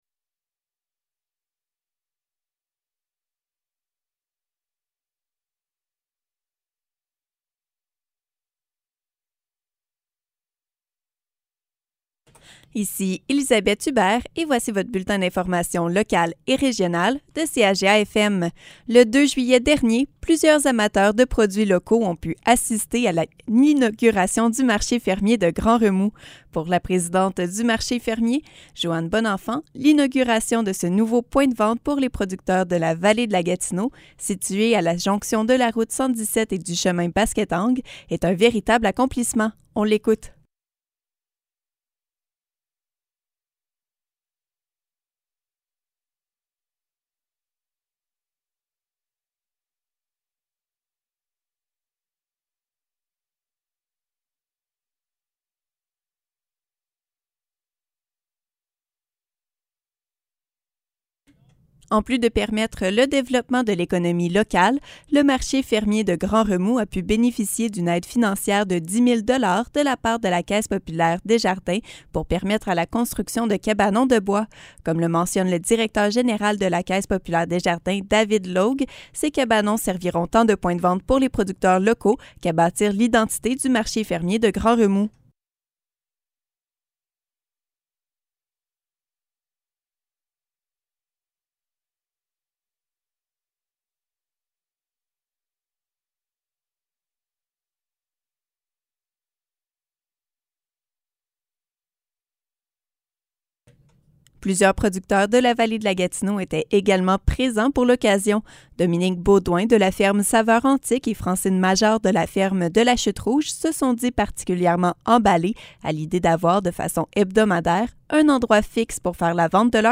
Nouvelles locales - 5 juillet 2021 - 12 h